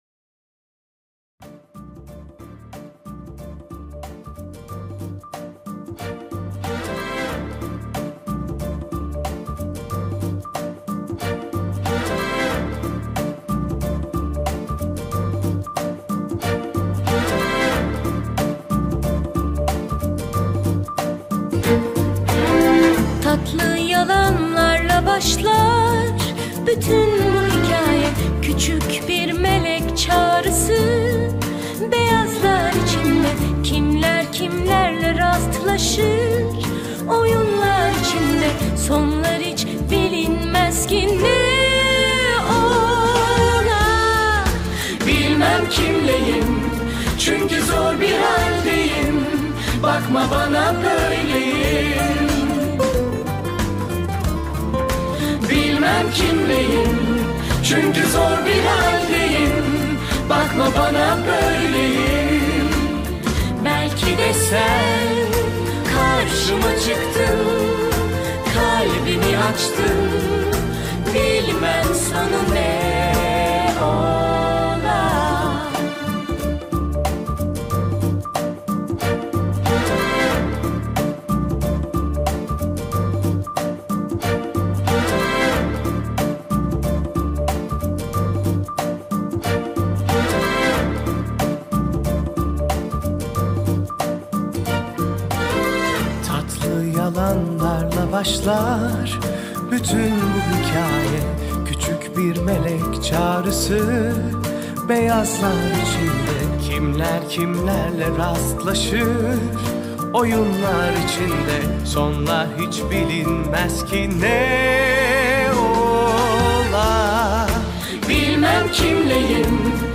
Dizi Müziği), mutlu huzurlu rahatlatıcı şarkı.